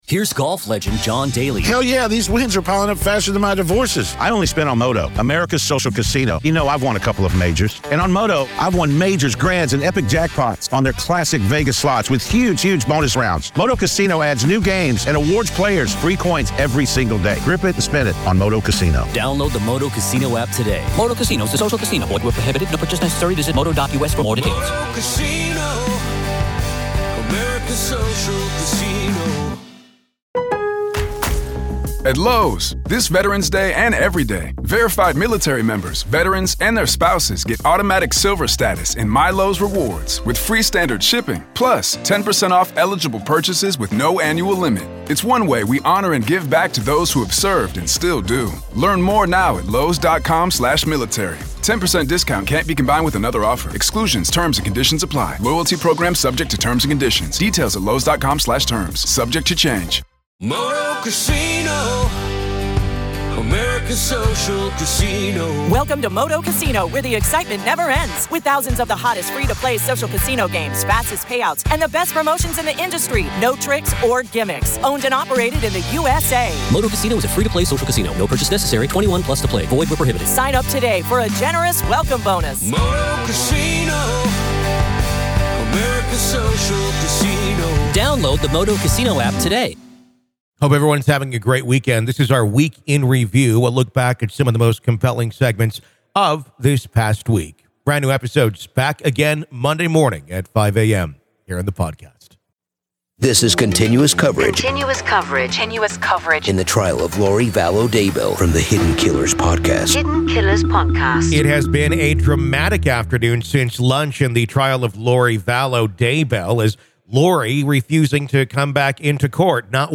Welcome to "The Week In Review," a riveting journey that takes you back through the most captivating interviews, gripping updates, and electrifying court audio from the cases that have captured our attention.